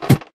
Sound / Minecraft / step / ladder4